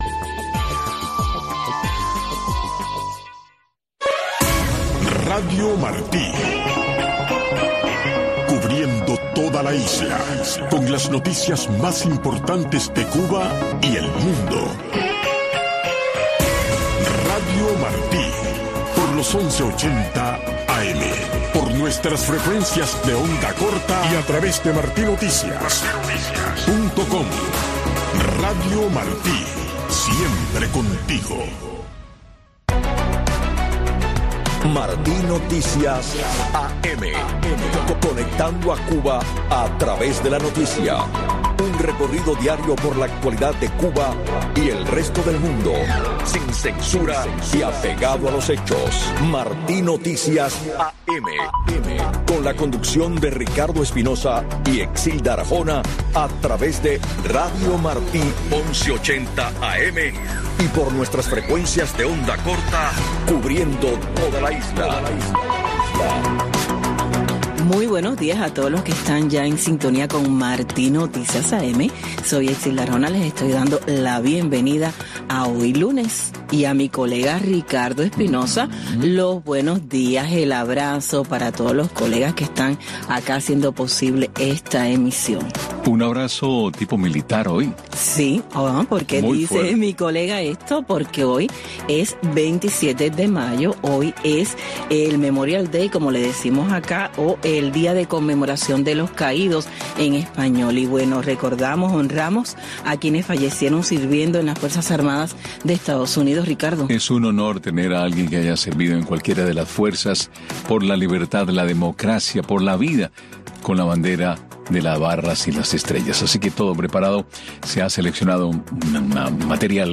Revista informativa con los últimos acontecimientos ocurridos en Cuba y el mundo. Con entrevistas y temas de actualidad relacionados a la política, la economía y de interés general.